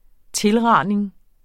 Udtale [ ˈtelˌʁɑˀneŋ ]